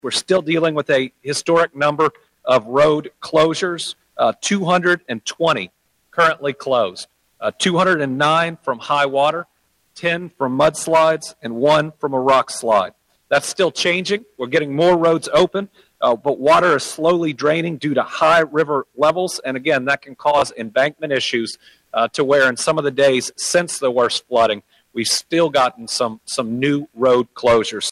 During a Wednesday morning “Team Kentucky” update from Frankfort, Governor Andy Beshear noted Kentucky State Police has received more than 12,500 calls for service in the last 24 hours.